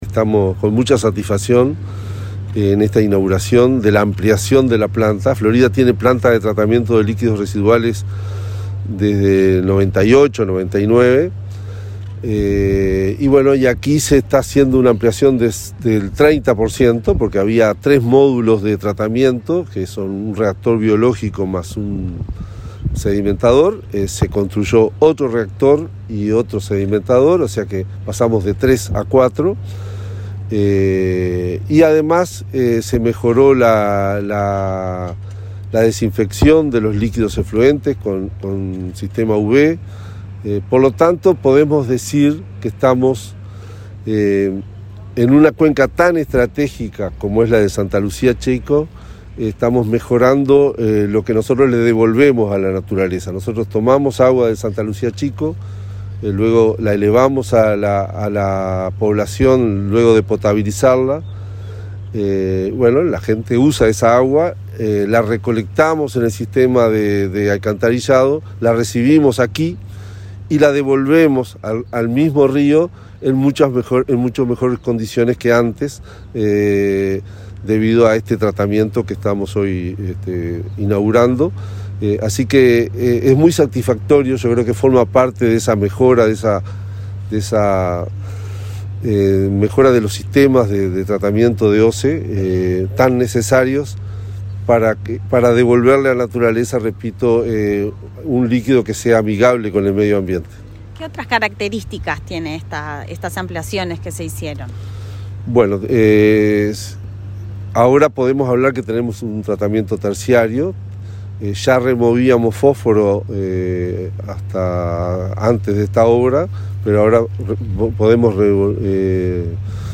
Entrevista al presidente de OSE, Raúl Montero
Este martes 17 en Florida, el presidente de la OSE, Raúl Montero, dialogó con Comunicación Presidencial, antes de inaugurar las obras de amplificación